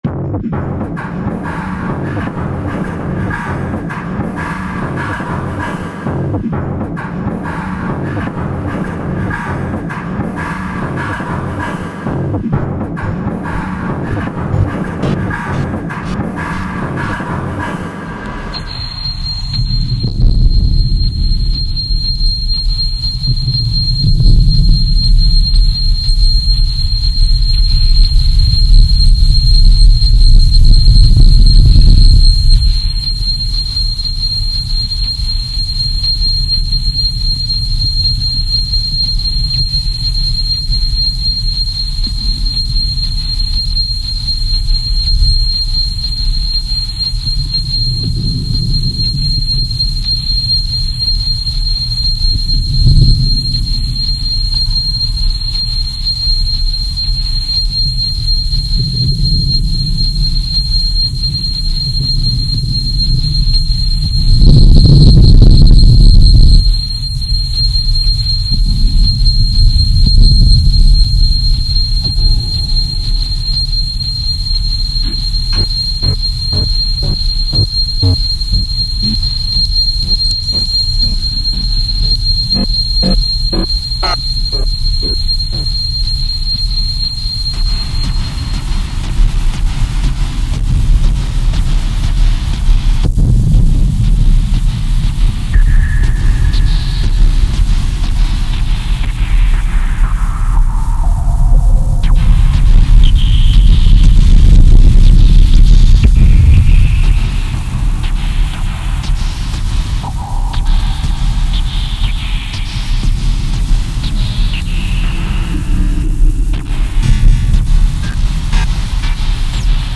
It’s experimental.
It ranges from field recordings to noise to 4-track dual mono sounds to whatever comes to mind.
— Kopfkino-like sound